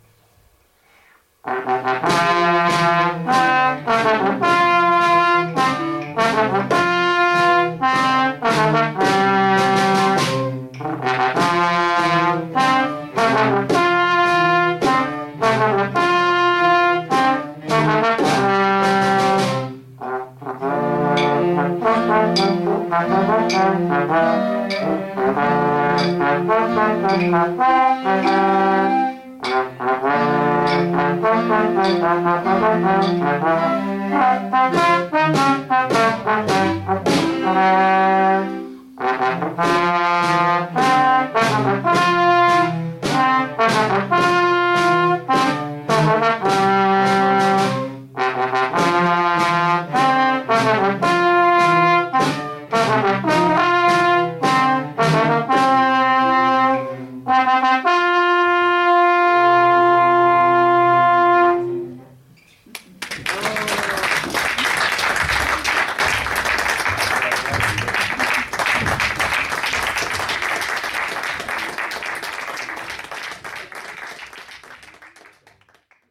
Concierto de verano de 2019